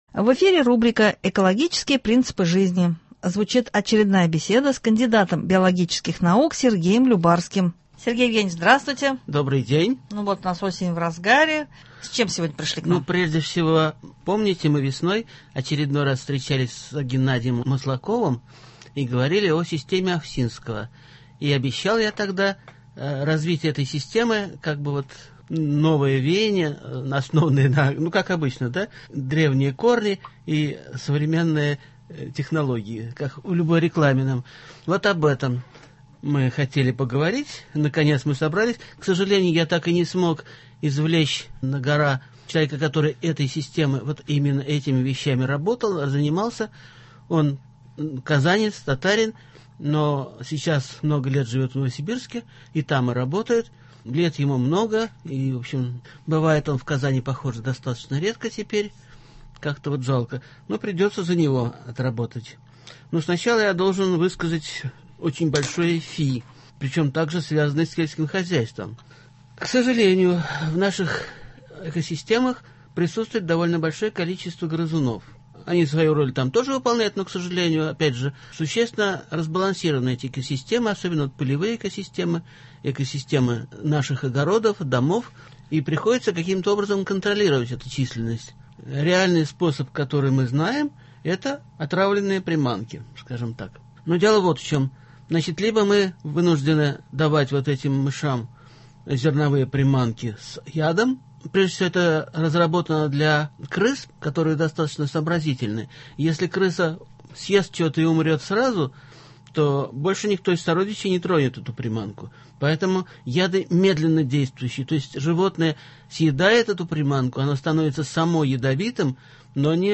В студии